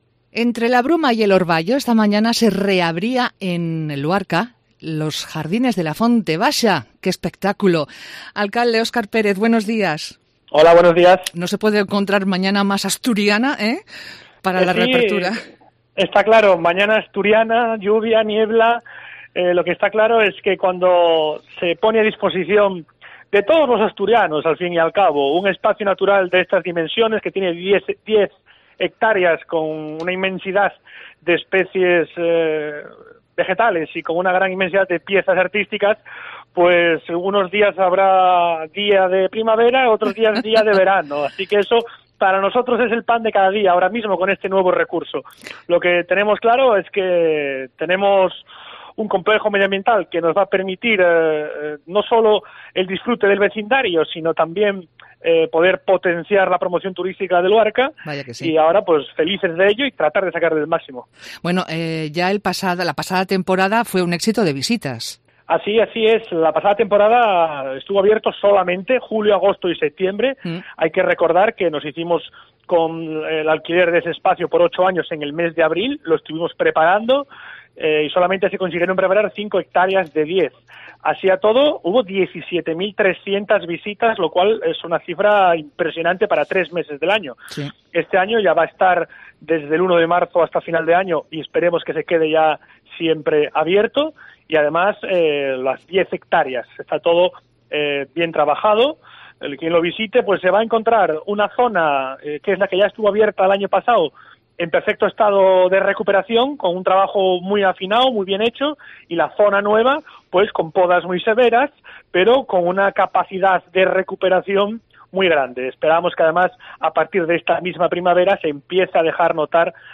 Entrevista con Oscar Pérez, alcalde de Valdés